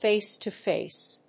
fáce to fáce